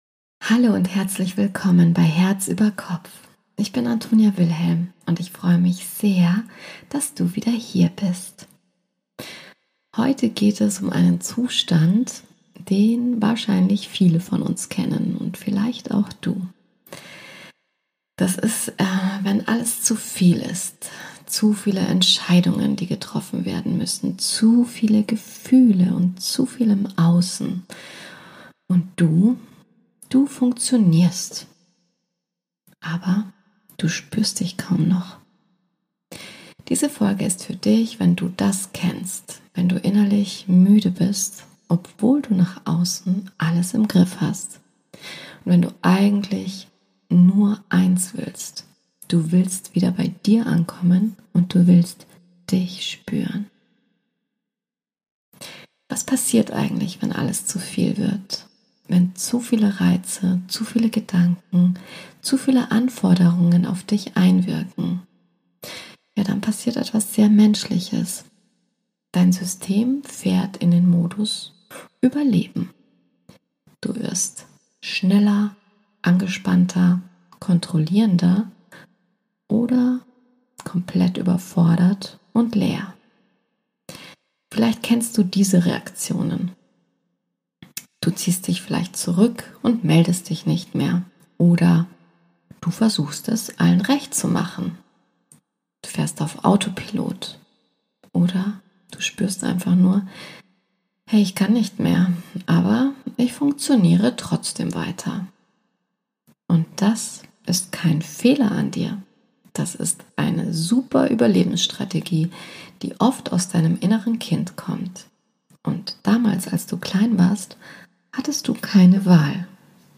Einfühlsam.
Mit geführter Hypnose „Der sichere Ort in Dir“ – für innere Ruhe und tiefe Verbindung mit Deinem inneren Kind.